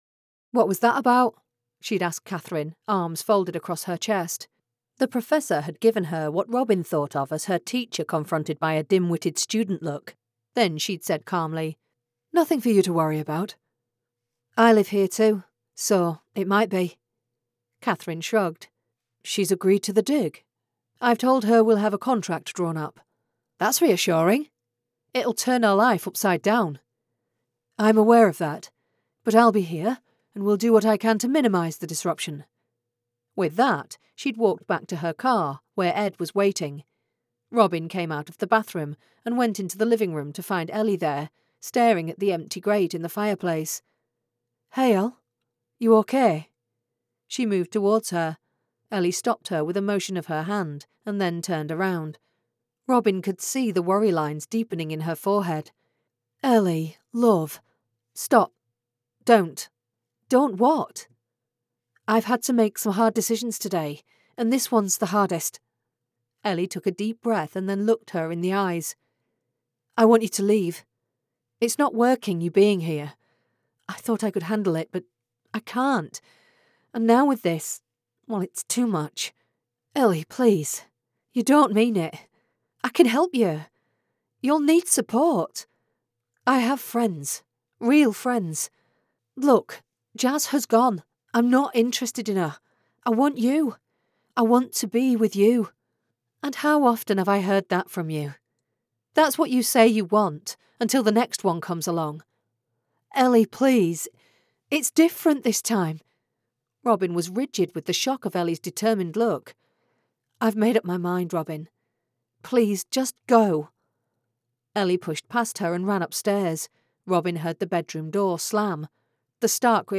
Starting Over by Jen Silver [Audiobook]